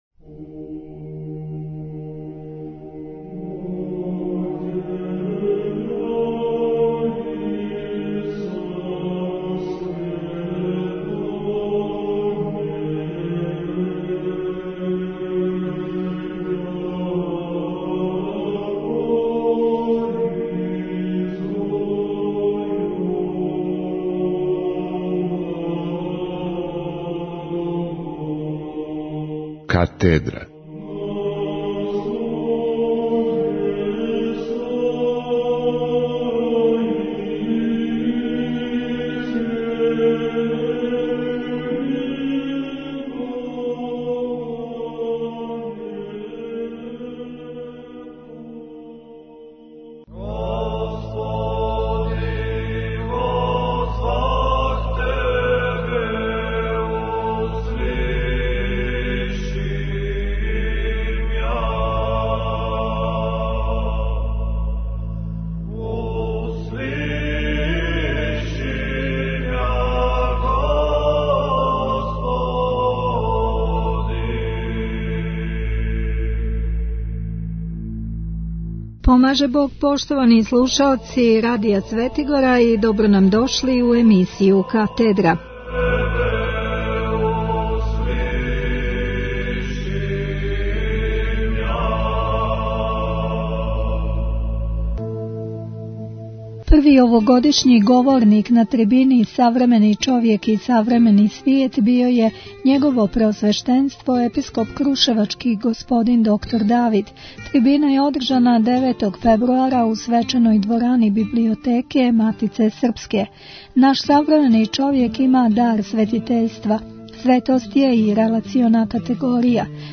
Први овогодишњи говорник на трибини Савремени човек и савремени свет био је Његово Преосвештенство Епископ крушевачки г. др Давид. Трибина је одржана у четвртак, 9. фебруара 2017. године, у свечаној дворани Библиотеке Матице српске.